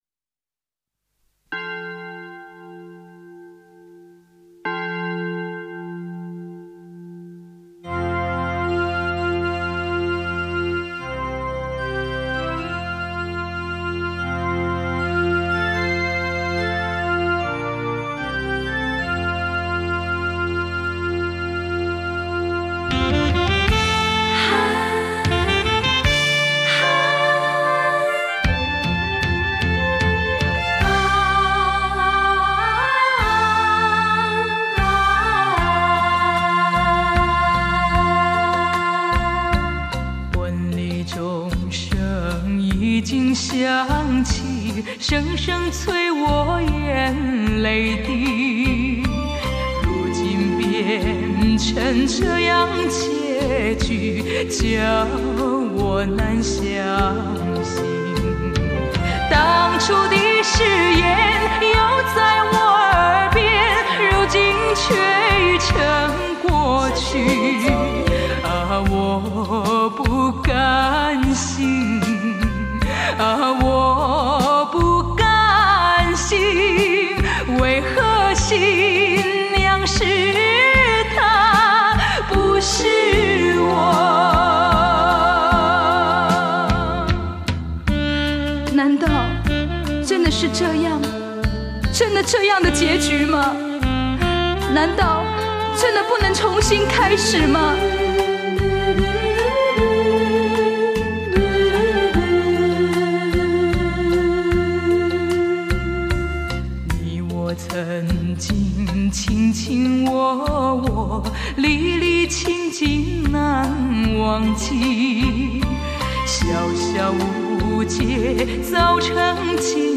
(情切切，意难忘。歌词悲戚，曲调伤感，唱腔凄婉，使人心酸落泪，太感人了！)